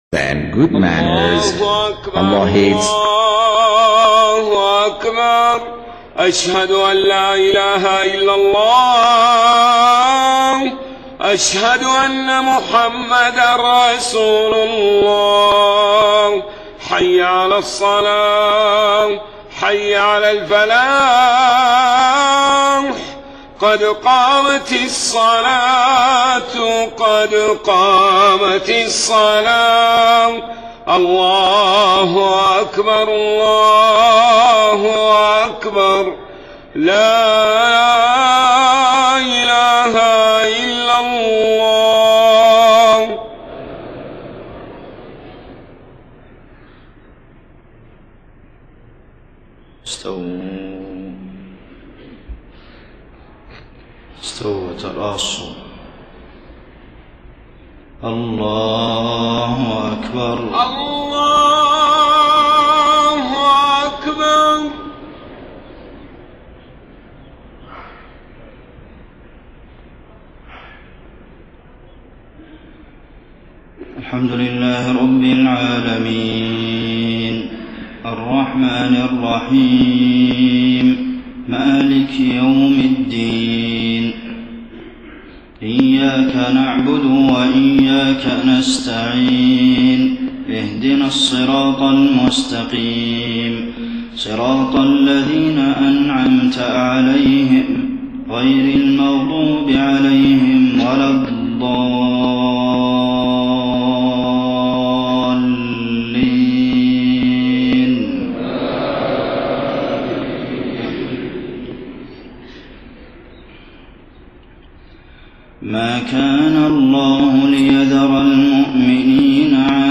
صلاة المغرب 19 محرم 1431هـ من سورة آل عمران 179-180 > 1431 🕌 > الفروض - تلاوات الحرمين